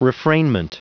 Prononciation du mot refrainment en anglais (fichier audio)
Prononciation du mot : refrainment